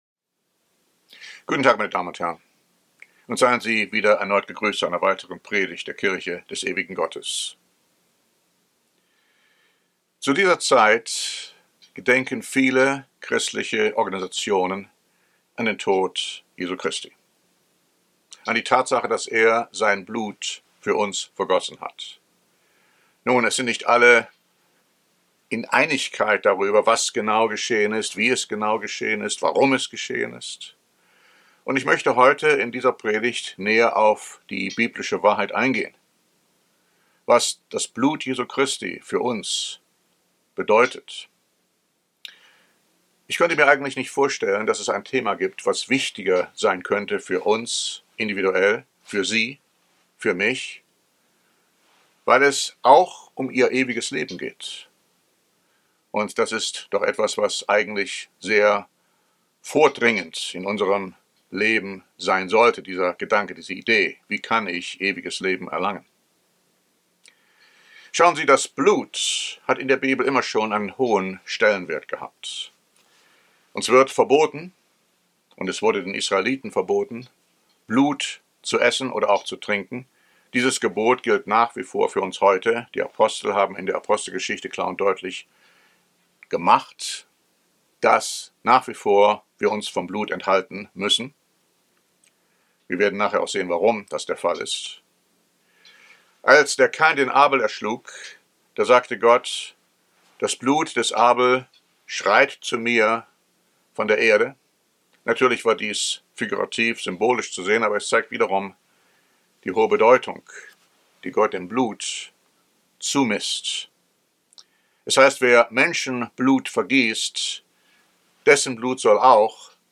Diese Predigt zeigt Ihnen, dass Christi vergossenes Blut für uns heilsnotwendig ist, und warum es so kostbar und unbezahlbar ist.